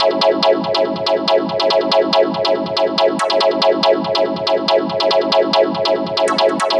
Stab 141-BPM F#.wav